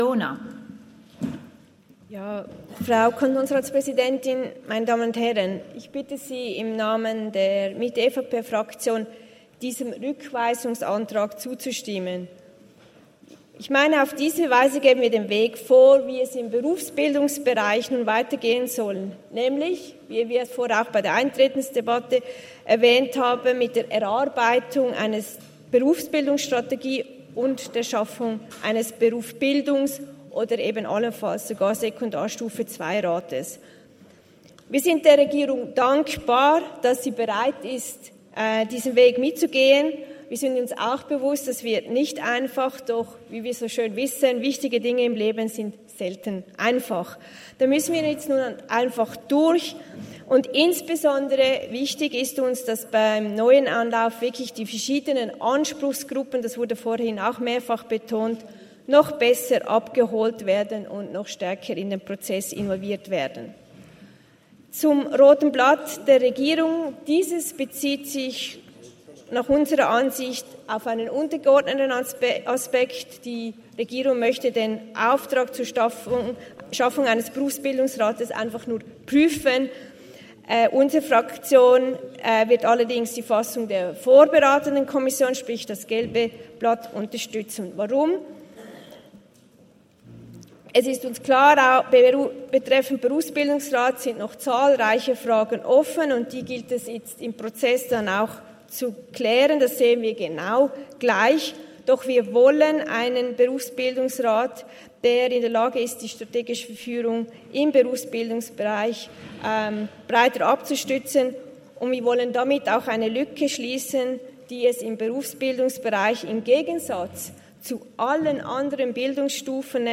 19.9.2023Wortmeldung
Session des Kantonsrates vom 18. bis 20. September 2023, Herbstsession